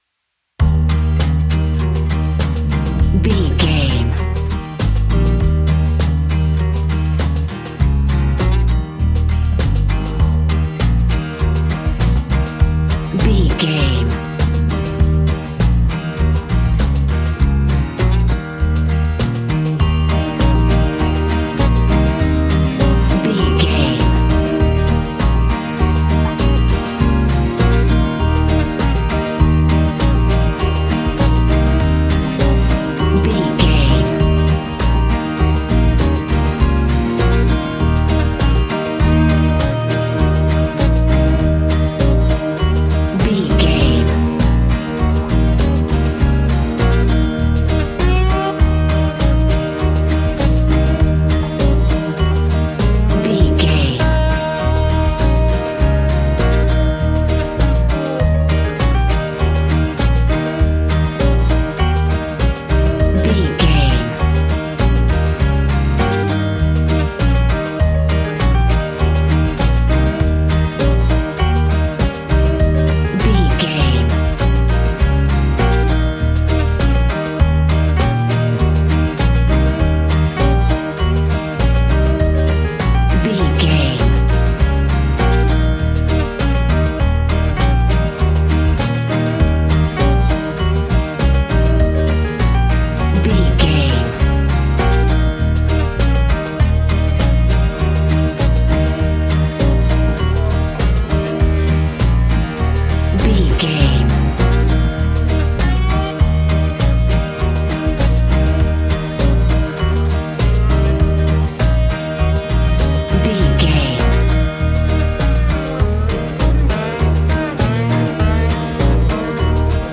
Country and folk music for the farmyard.
Ionian/Major
E♭
Fast
fun
bouncy
positive
double bass
drums
acoustic guitar